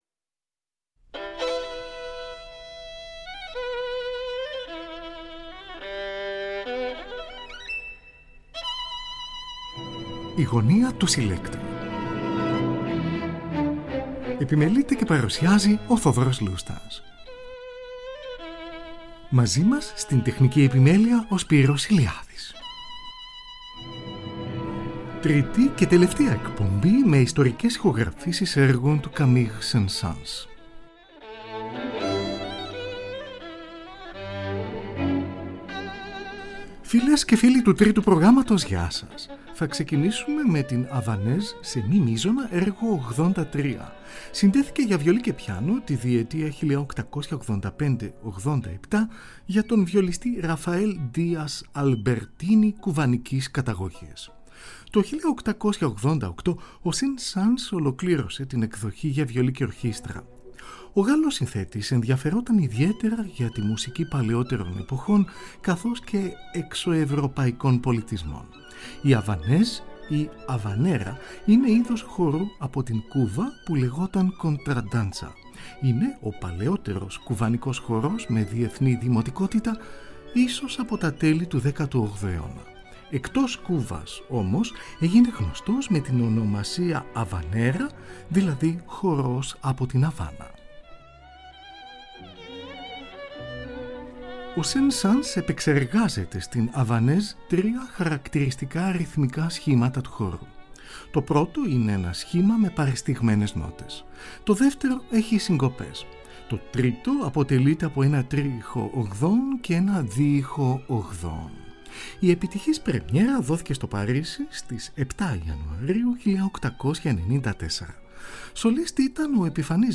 Ιστορικές Ηχογραφήσεις